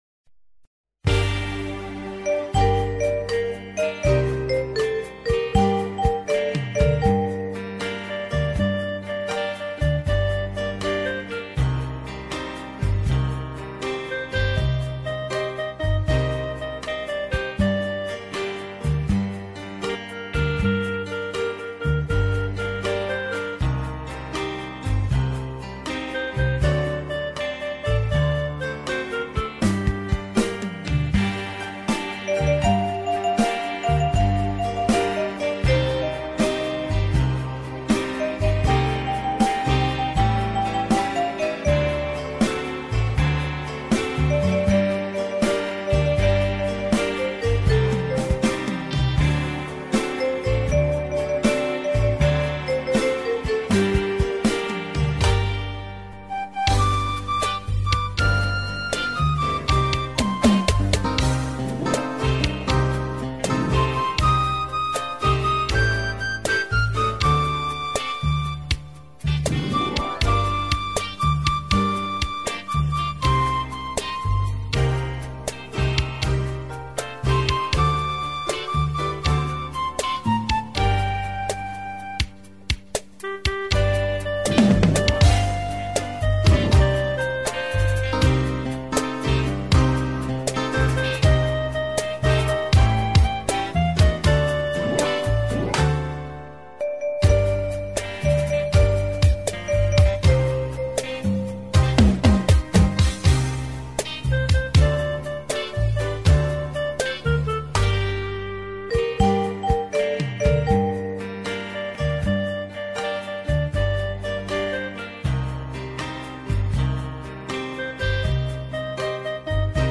20 б Дзвоник Gm.mp3